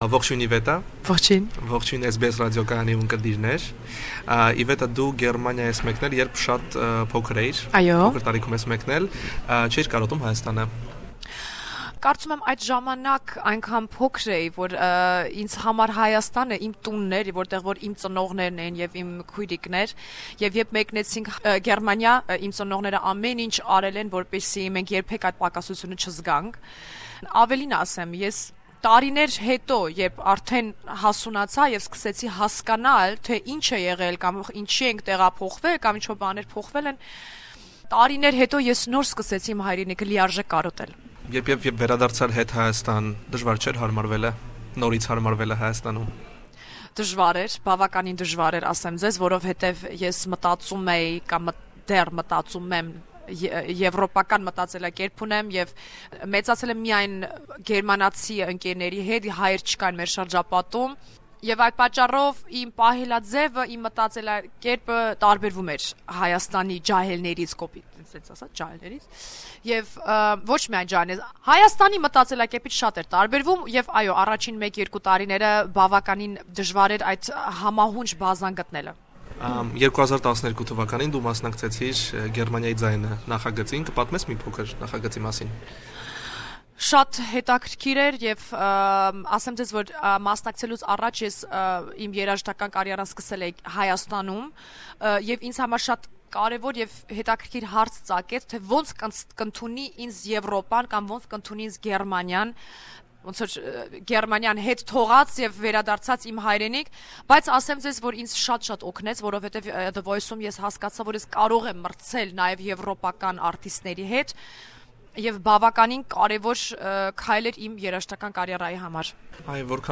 Interview with Iveta Mukuchyan representing Armenia in Eurovision 2016 song contest.